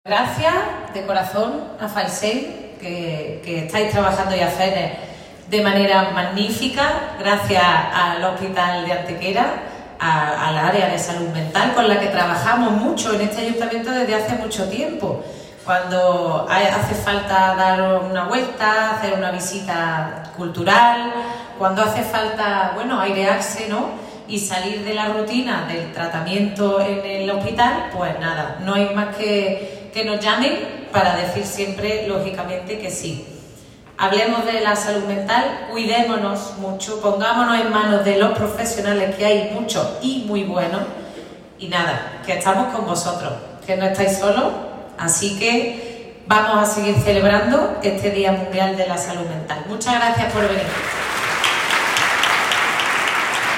El patio del Ayuntamiento de Antequera ha acogido en la mañana de hoy jueves 10 de octubre la lectura simbólica del manifiesto conmemorativo del Día Mundial de la Salud Mental, que este año se celebra bajo el lema "Trabajo y Salud Mental" haciendo referencia a la necesaria integración laboral en la sociedad que necesitan los enfermos de este tipo.
Cortes de voz